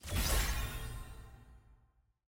sfx-s17-collection-unlock-celebration.ogg